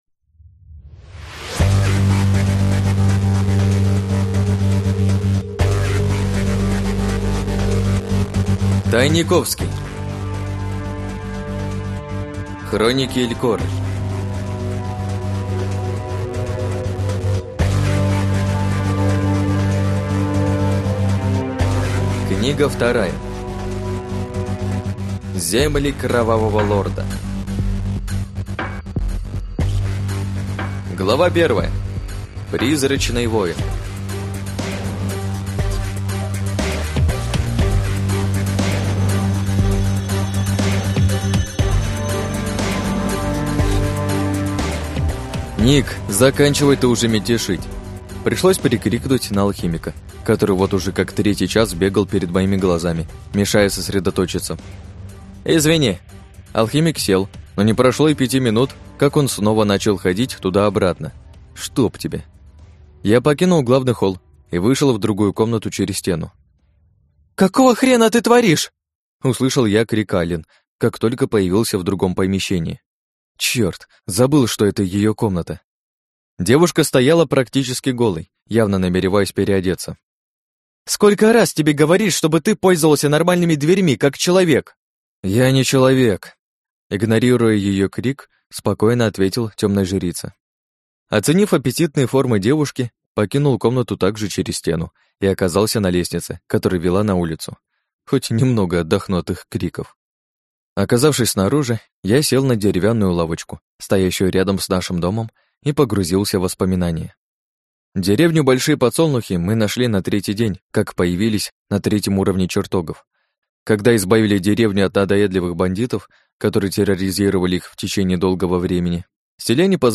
Аудиокнига Хроники Илькоры. Земли кровавого лорда | Библиотека аудиокниг